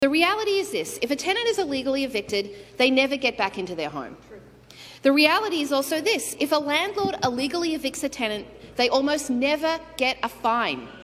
The ongoing struggle of tenants in several Trenton apartment buildings to stay in their apartments despite eviction notices was a topic of discussion Wednesday at the provincial legislature.